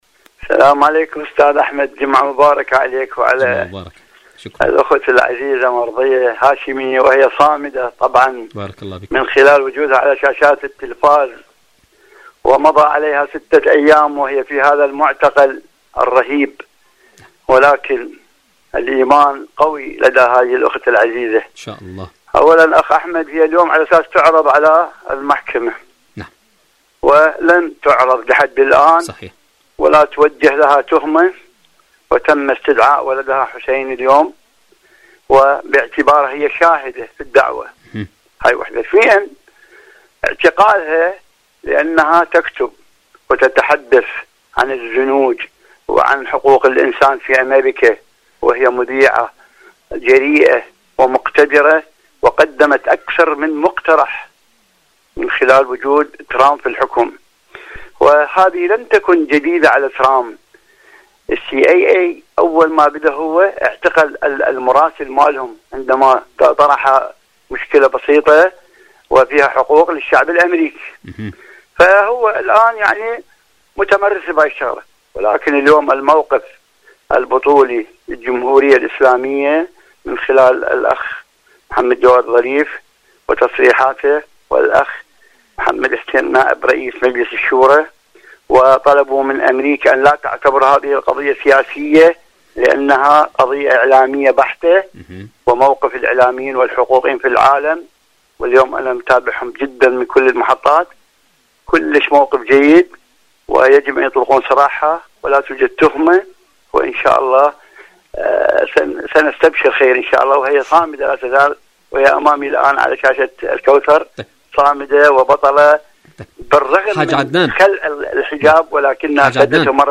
ألو طهران / مشاركة هاتفية